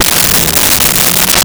Pipe Groan 01
Pipe Groan 01.wav